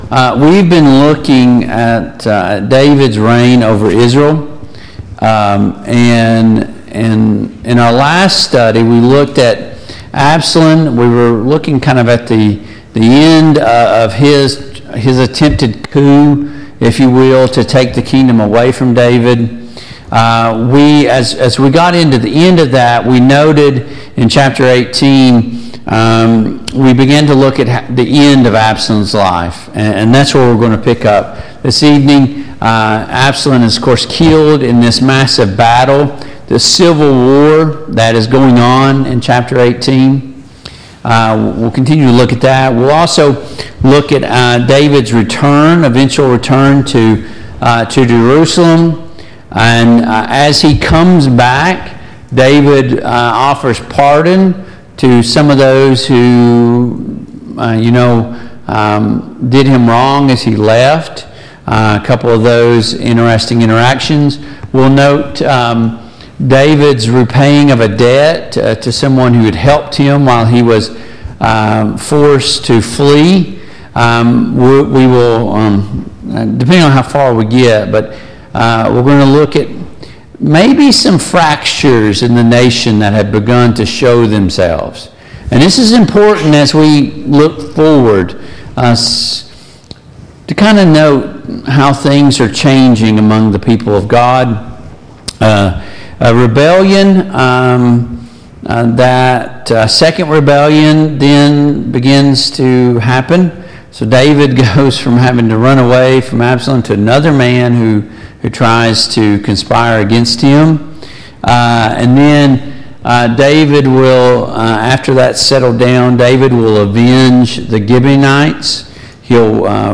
The Kings of Israel Passage: II Samuel 18, II Samuel 19, II Samuel 20 Service Type: Mid-Week Bible Study Download Files Notes « 4.